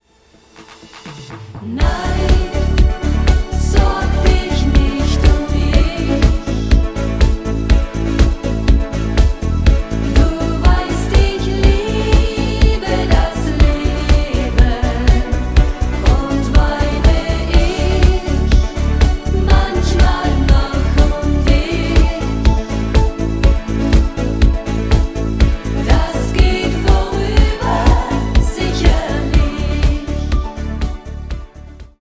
Discofox & Schlager